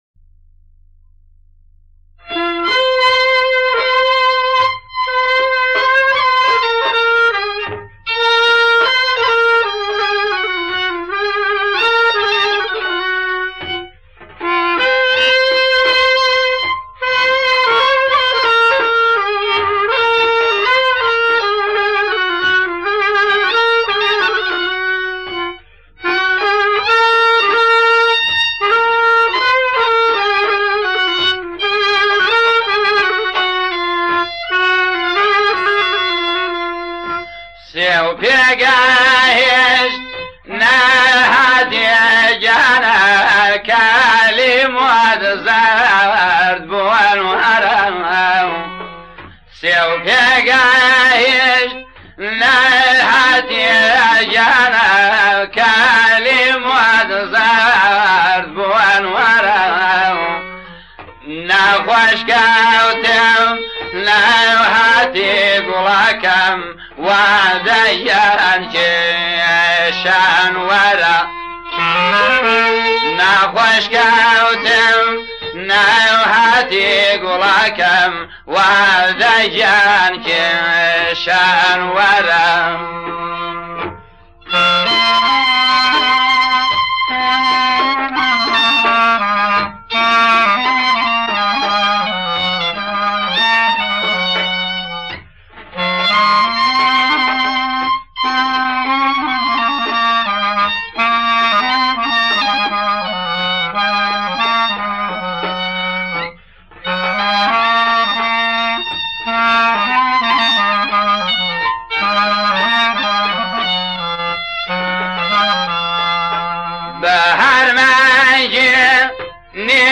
آهنگ کوردی